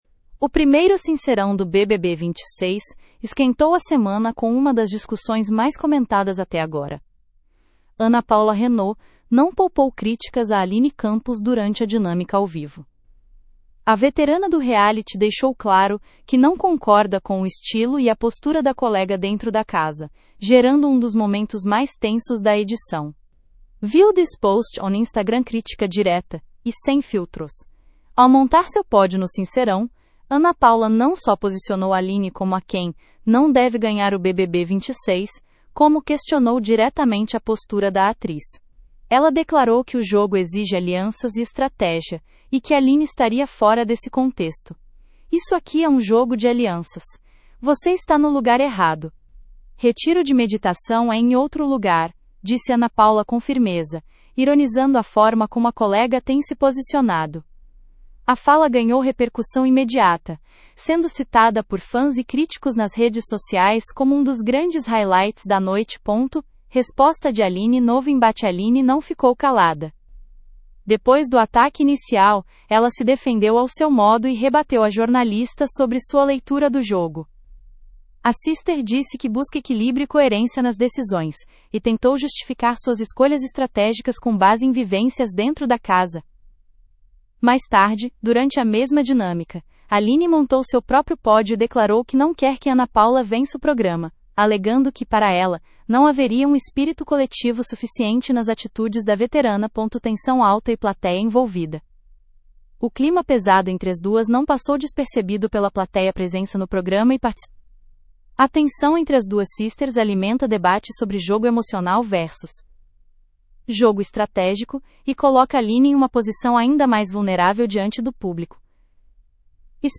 O primeiro Sincerão do BBB 26 esquentou a semana com uma das discussões mais comentadas até agora: Ana Paula Renault não poupou críticas a Aline Campos durante a dinâmica ao vivo.
“Isso aqui é um jogo de alianças. Você está no lugar errado. Retiro de meditação é em outro lugar”, disse Ana Paula com firmeza, ironizando a forma como a colega tem se posicionado.
O clima pesado entre as duas não passou despercebido pela plateia presente no estúdio, que voltou a marcar presença no programa e participou ativamente com o pipocômetro, avaliando a performance dos participantes em cada momento da dinâmica.